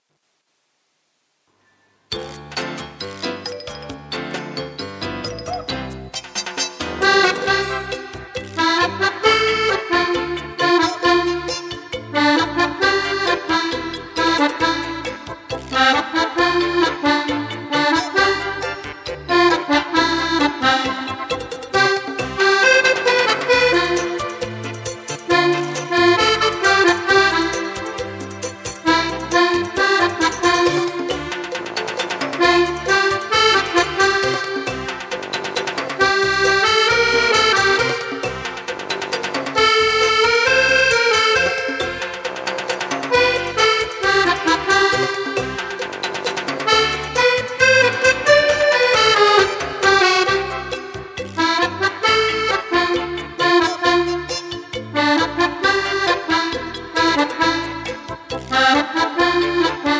(Cha cha)